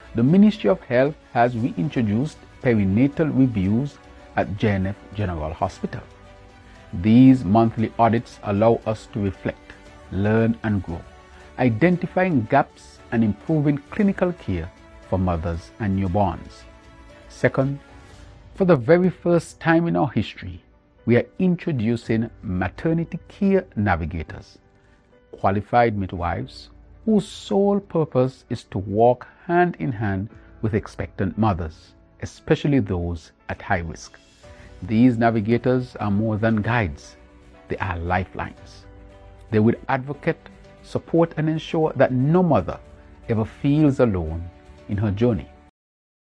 Prime Minister, Dr. Terrance Drew.